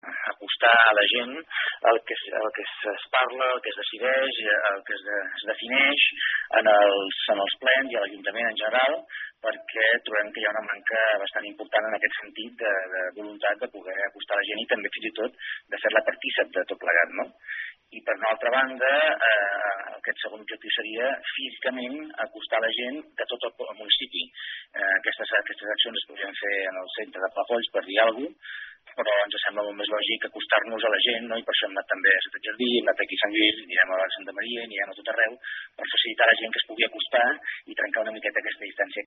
La voluntat és acostar a la gent les actuacions des de l’Ajuntament. Són declaracions de Francesc Alemany, portaveu d’ERC al consistori.